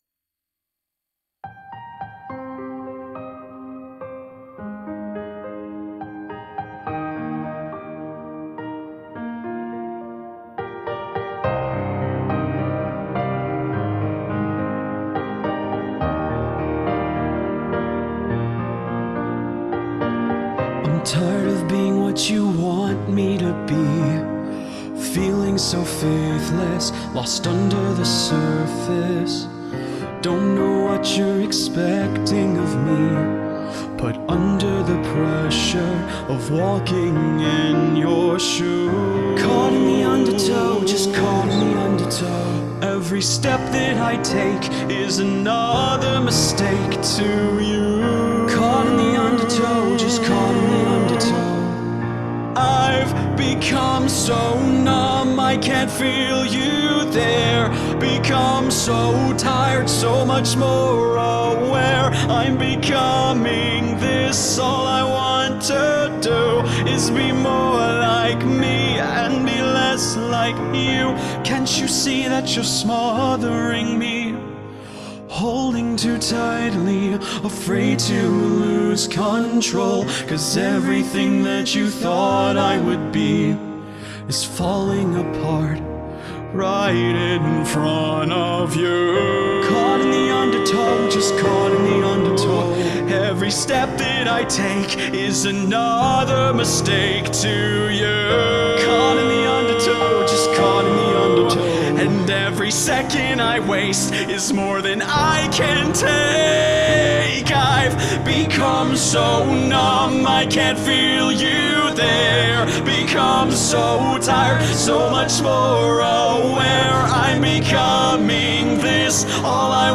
Ballad Vocal Cover